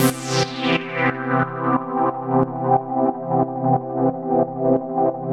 GnS_Pad-alesis1:8_90-C.wav